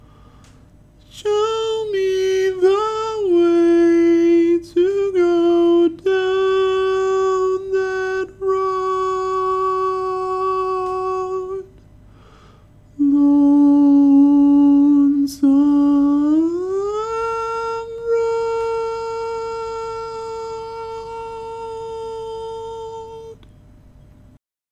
Key written in: D Major
Type: Barbershop
Each recording below is single part only.